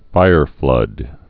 (fīrflŭd) or fire·flood·ing (-flŭdĭng)